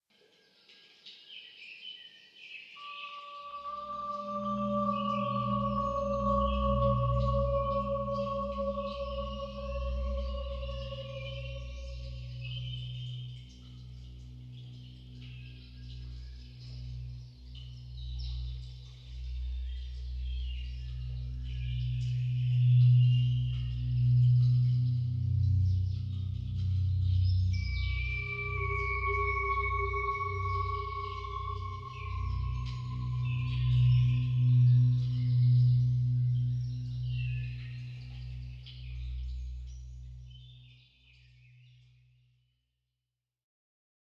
ambient reimagining of his own recording from Norton Canes service station, UK.